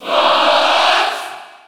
Category:Crowd cheers (SSB4) You cannot overwrite this file.
Fox_Cheer_French_PAL_SSB4.ogg.mp3